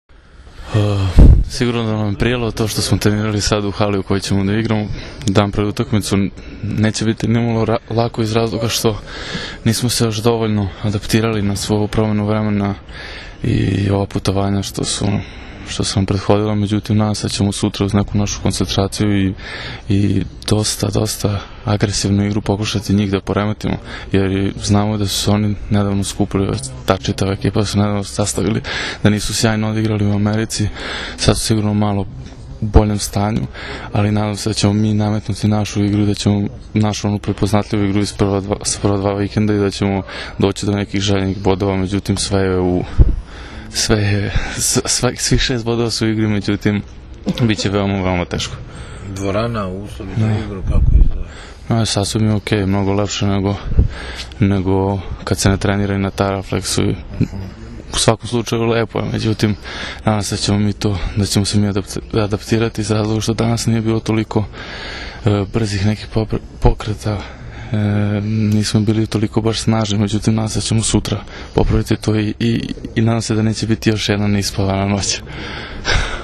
IZJAVA NIKOLE JOVOVIĆA